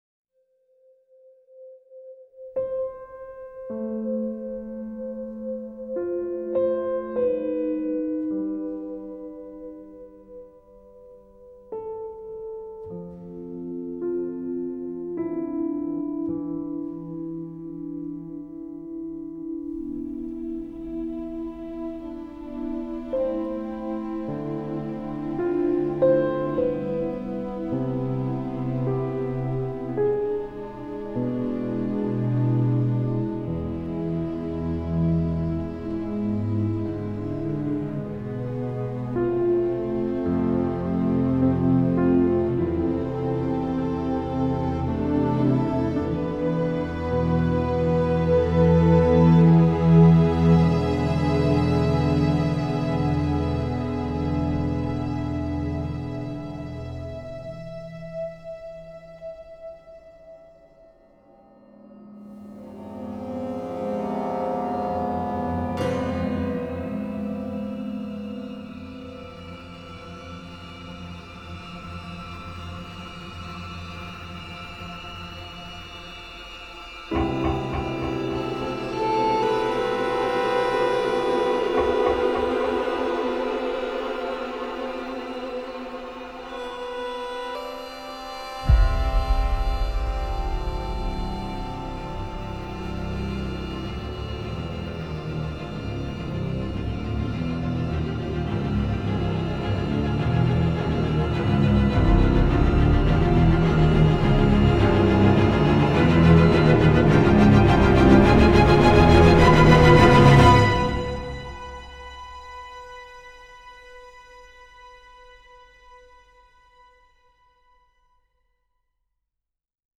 Banda sonora completa